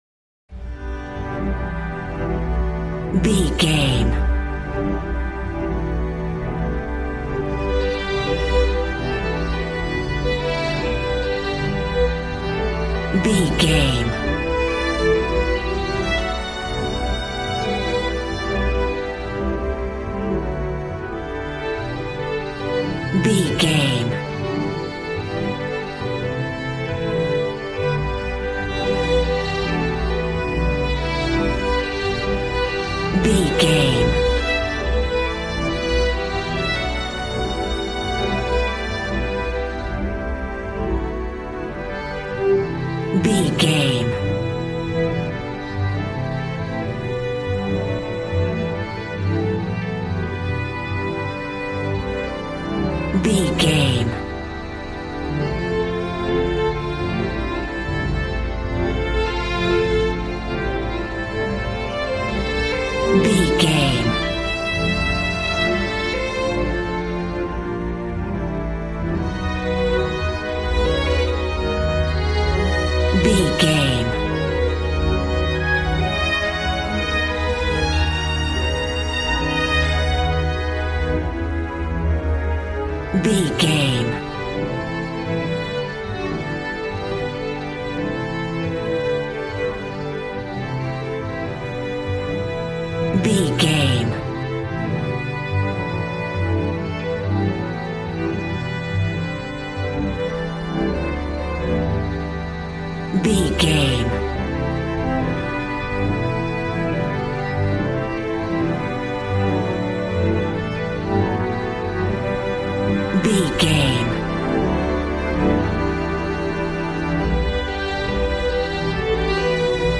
Aeolian/Minor
joyful
conga
80s